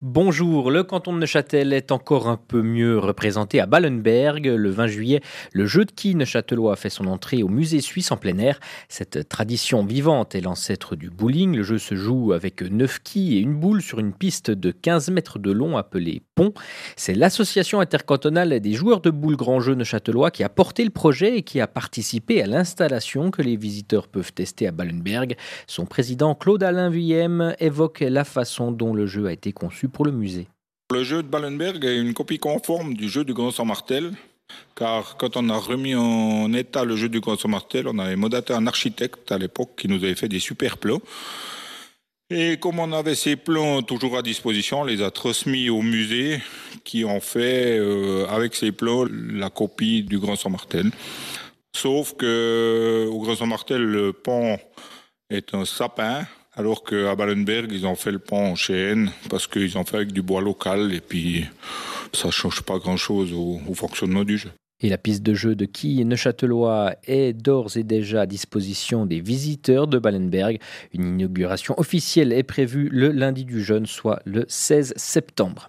Interviews
Version audio 1 (journal matin)